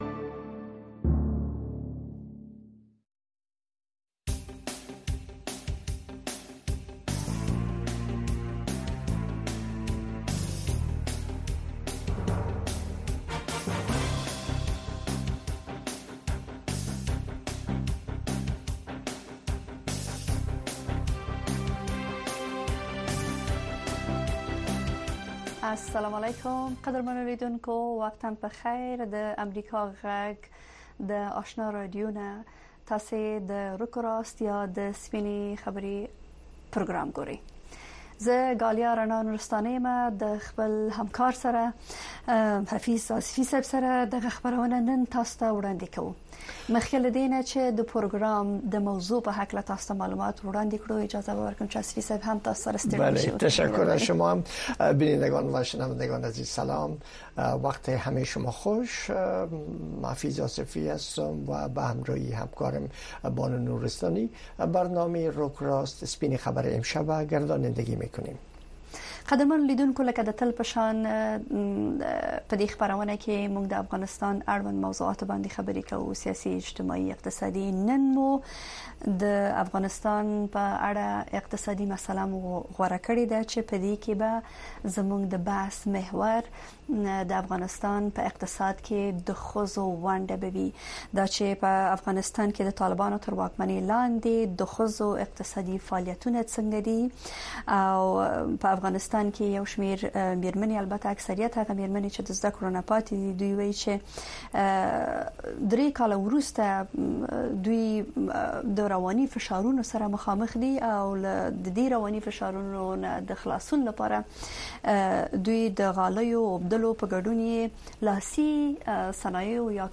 د ورځې په خبرونو اومسایلو د نظر د خاوندانو سپینې خبرې او د اوریدونکو نظرونه